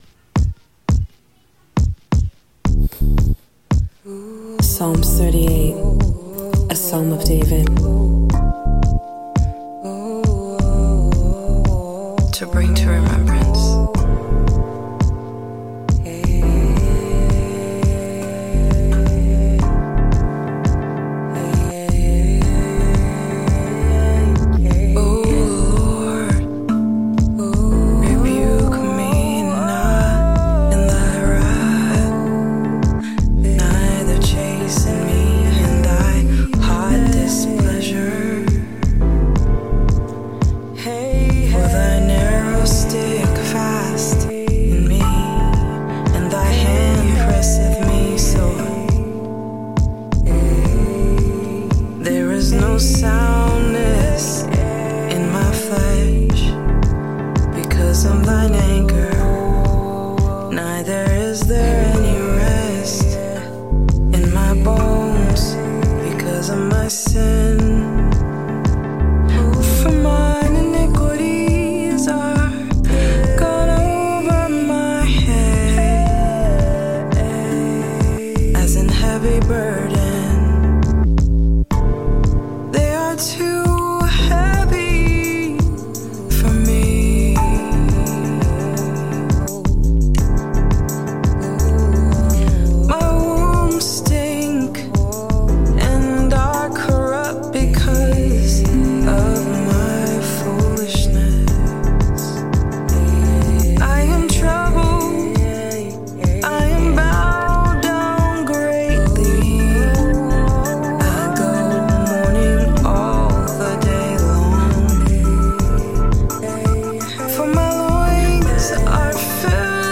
Rav vast drum
tongue drum